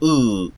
பின் இதழ்விரி உயிர்
Close_back_unrounded_vowel.ogg.mp3